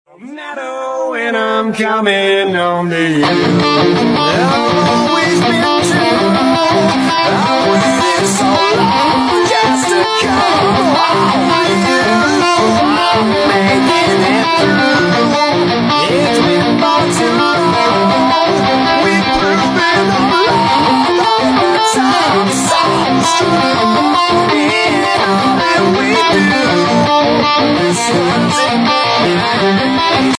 demolish wall again 🤛🏻 sound effects free download